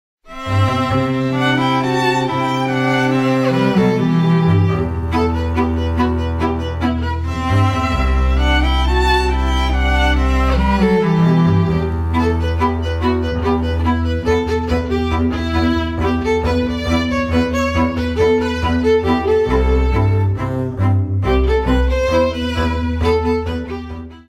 Bass
Fiddle
Cello
guitar